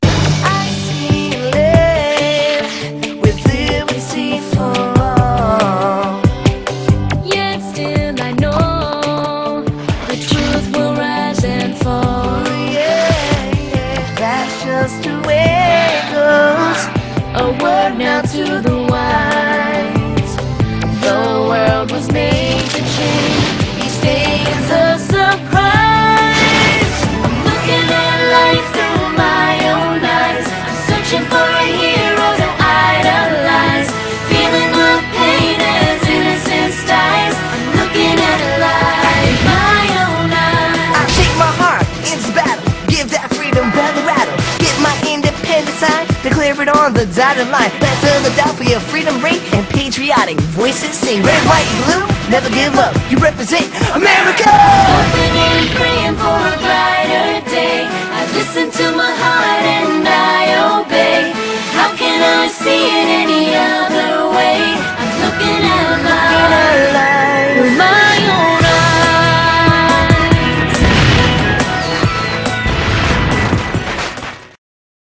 BPM140
Audio QualityCut From Video
Music: Ripped from Craving Explorer, using the OP version